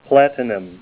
Help on Name Pronunciation: Name Pronunciation: Platinum
Say PLATINUM Help on Synonym: Synonym: ICSD 64917   PDF 4-802